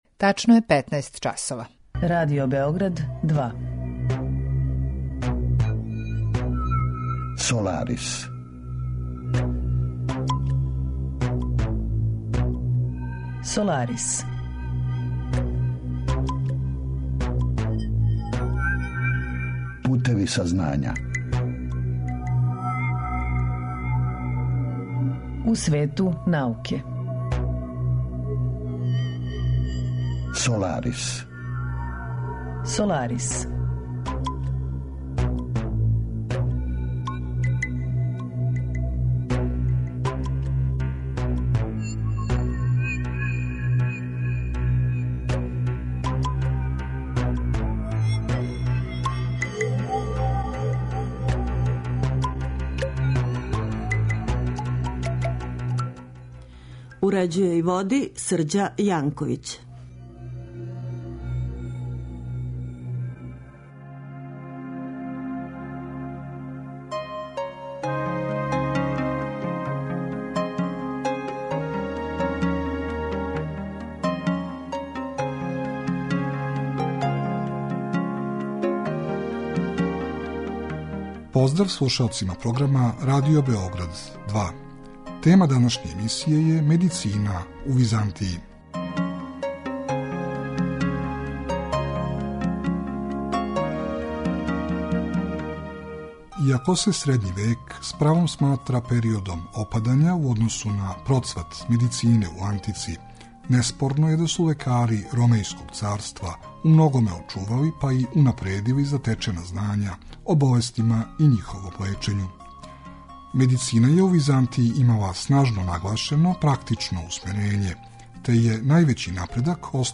Саговорник je проф. др